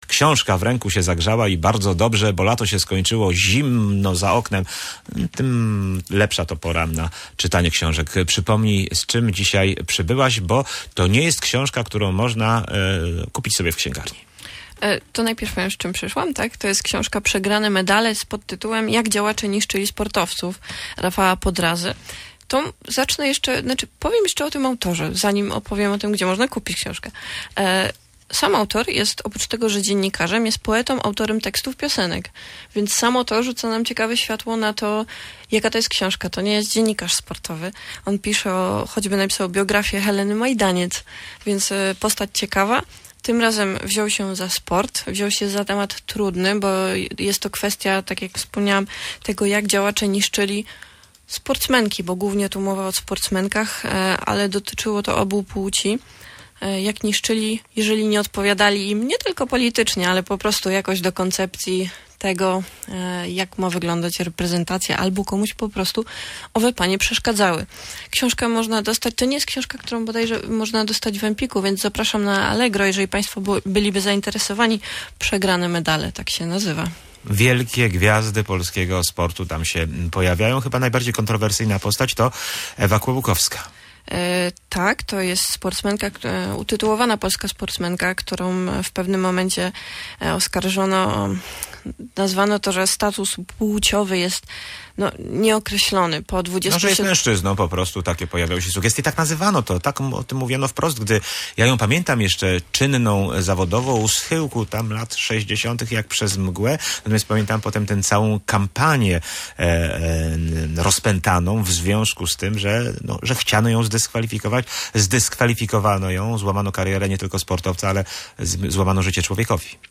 – To ważne, że pojawiła się taka pozycja na rynku książek sportowych – opowiada w rozmowie